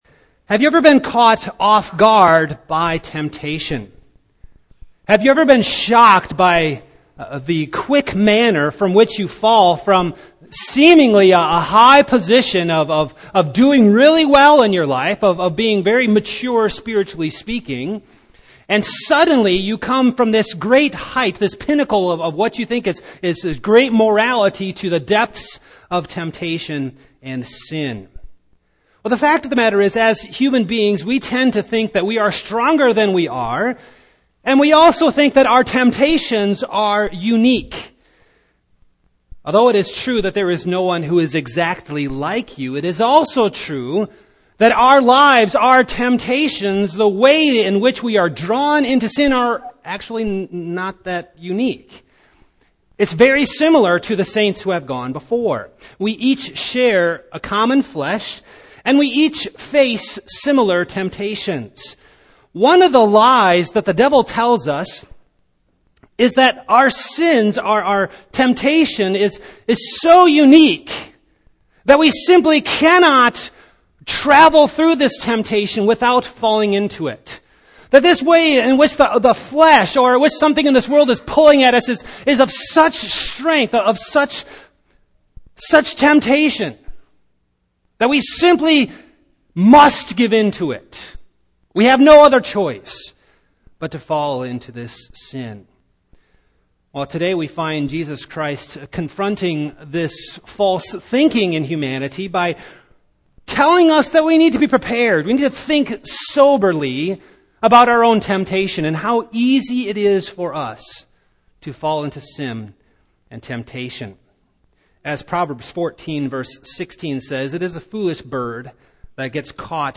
Passage: Luke 17:1-4 Service Type: Morning